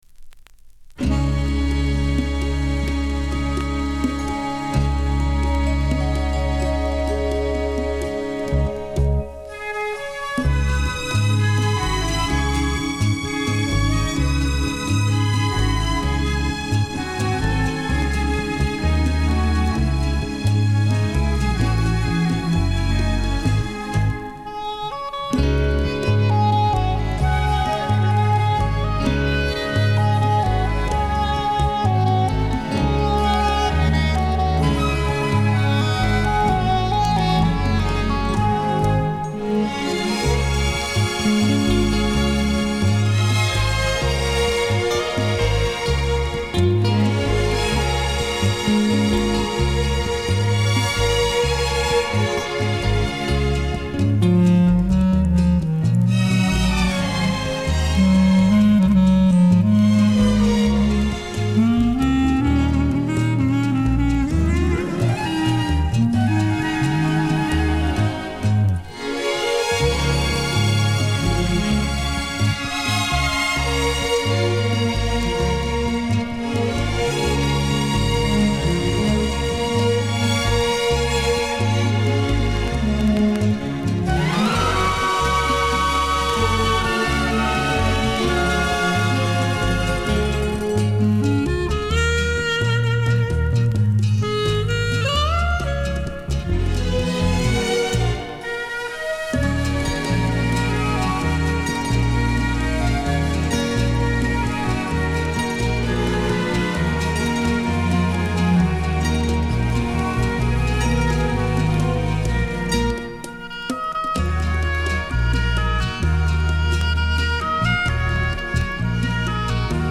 Genre:Latin
Style:Tango, Bolero